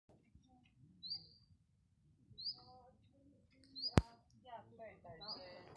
Chiffchaff, Phylloscopus collybita
Ziņotāja saglabāts vietas nosaukumsKrāslavas nov. Ūdrīšu pag.
StatusAgitated behaviour or anxiety calls from adults